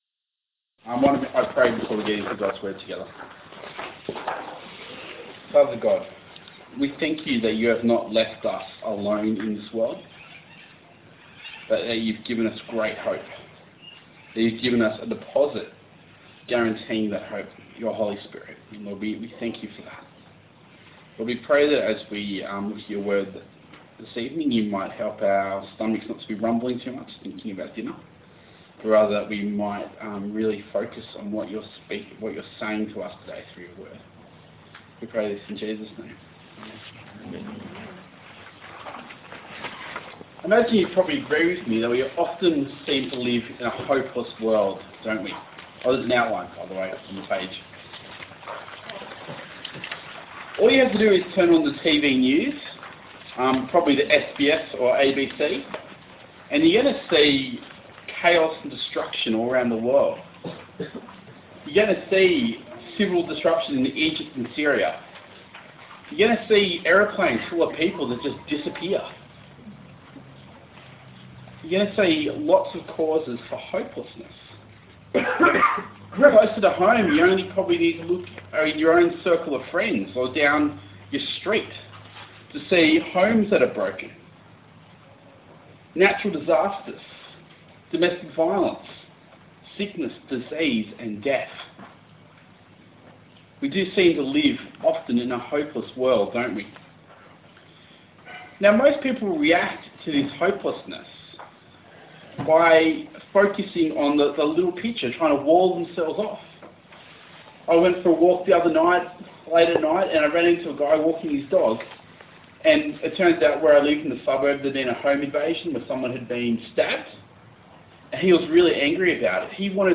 Passage: 2 Corinthians 5:1-10 Talk Type: Getaway